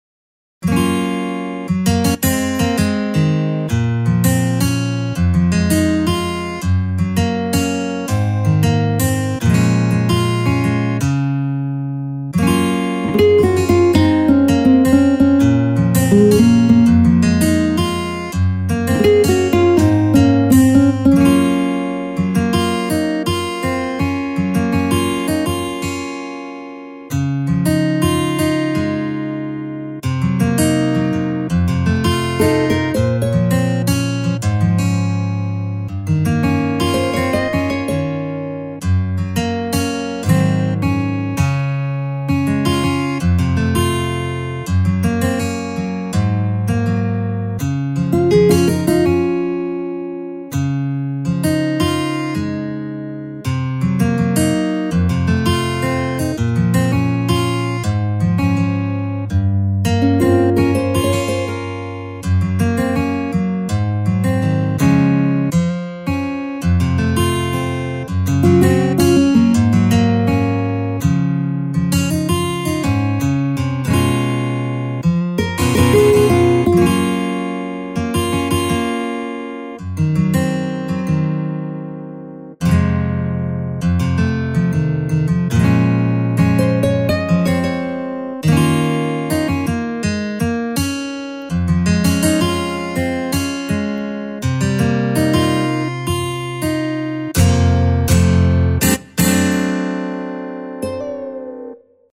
HALion6 : A.Guitar
Bright Acoustic Steel